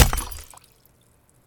sfx_tcell_blown.wav